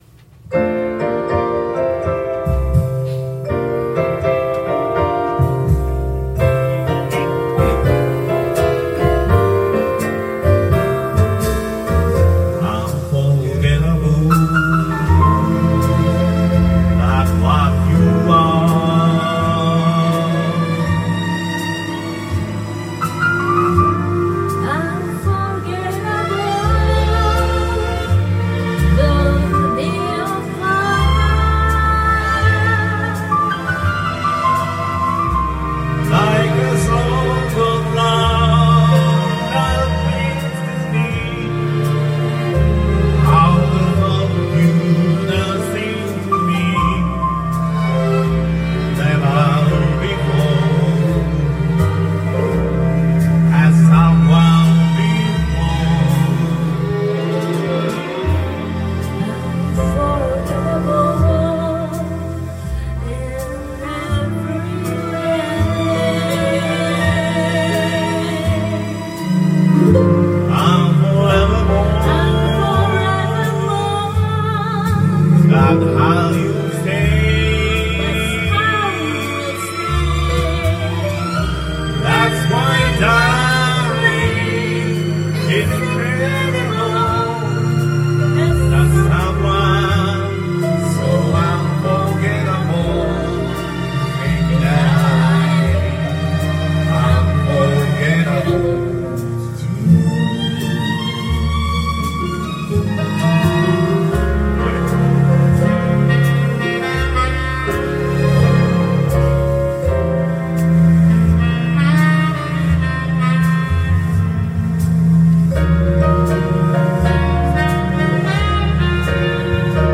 Duet & Chorus Night Vol. 18 TURN TABLE